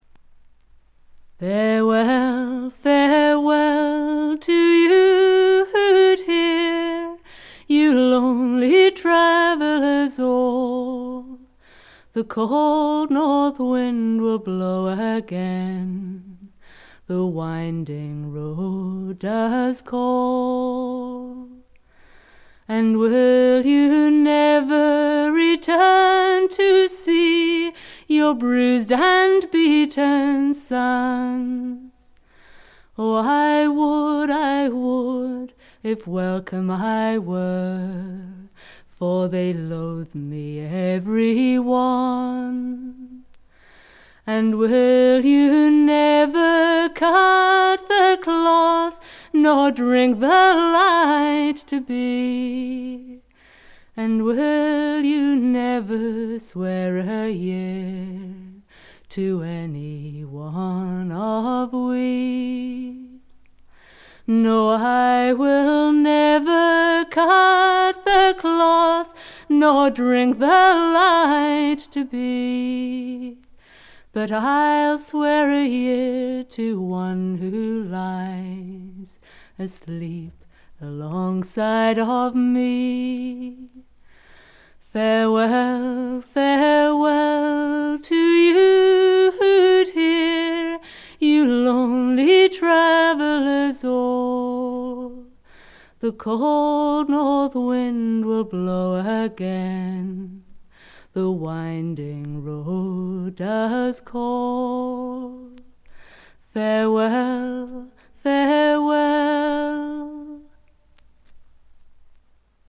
試聴曲は現品からの取り込み音源です。
Vocals
Guitar, Flute